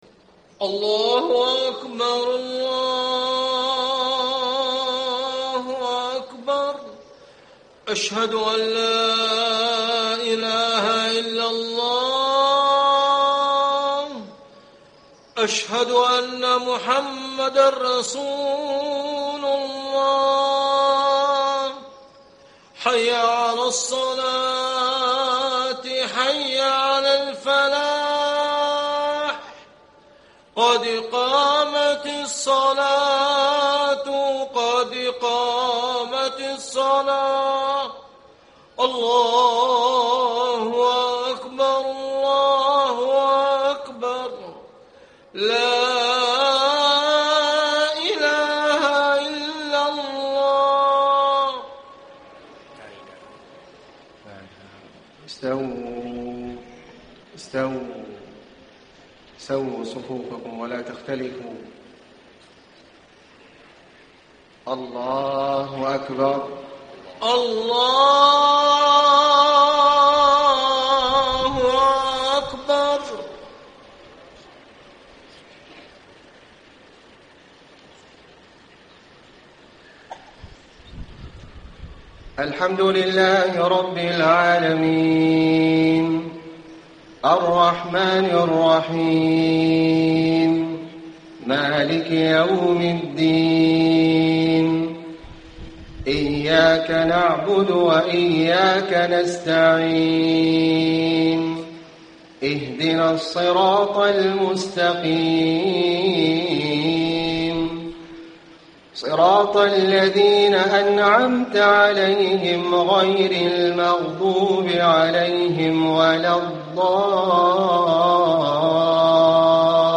صلاة المغرب 8 - 7 - 1435هـ سورتي الزلزلة و الماعون > 1435 🕋 > الفروض - تلاوات الحرمين